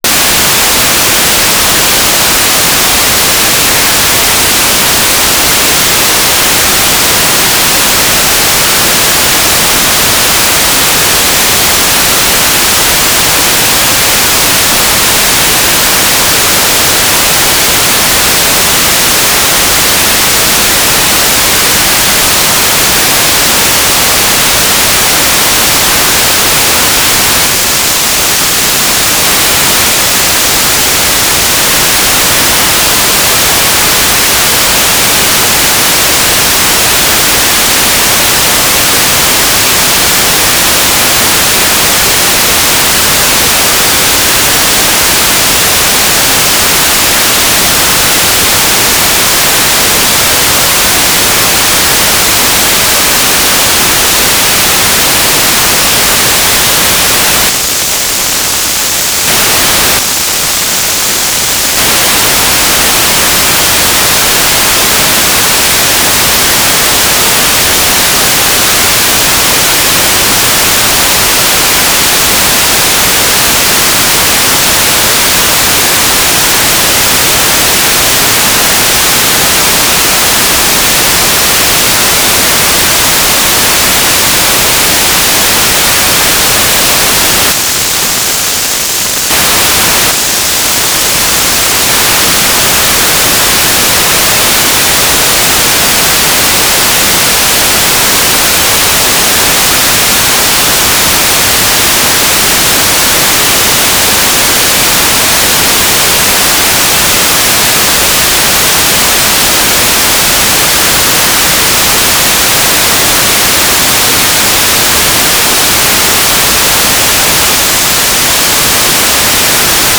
"station_name": "Drachten",
"transmitter_description": "Mode U - GMSK 2k4 USP FEC",
"transmitter_mode": "GMSK USP",